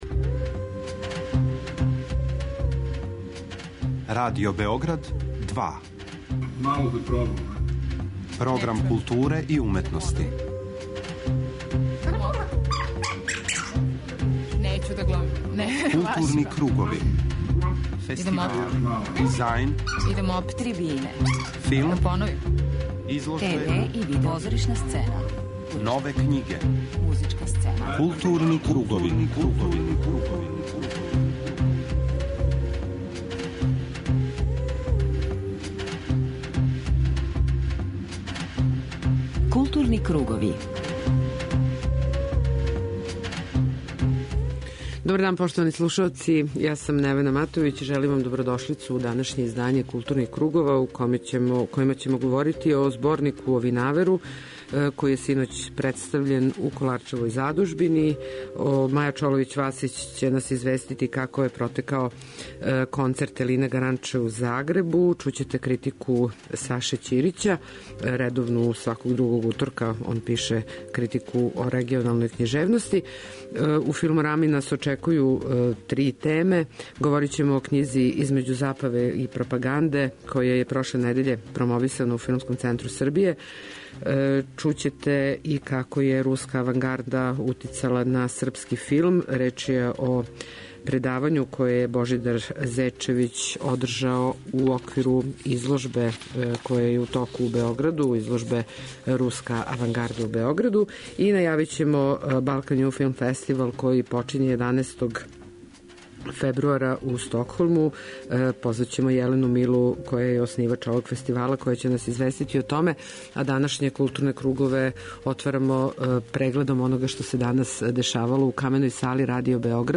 У данашњој емисији чућете неке сегменте овог свеобухватног и интересантног предавања, које је трајало више од три сата.